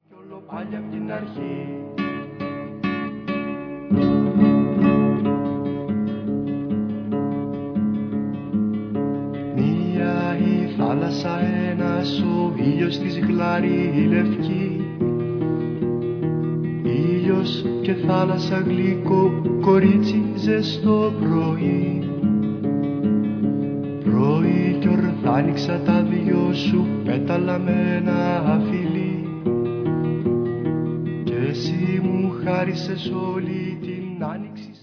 Τραγουδά ο συνθέτης.